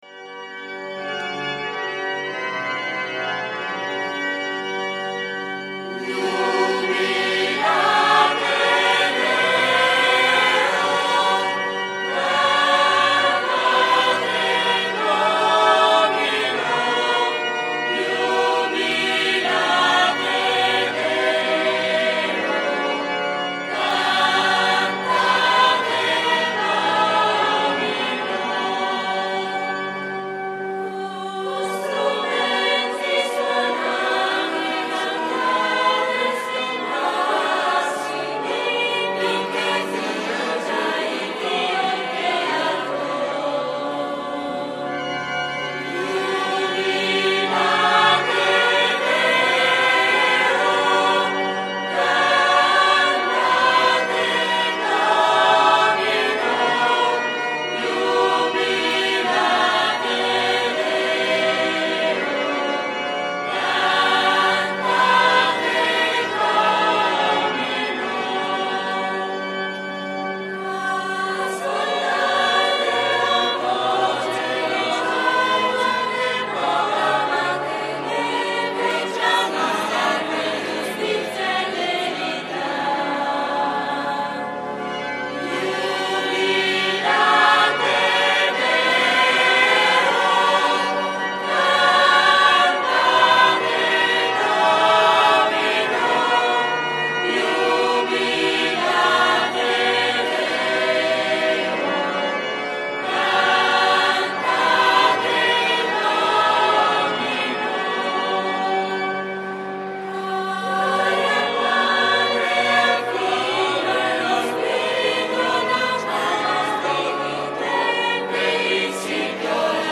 Veglia e Messa della notte di Natale
canto: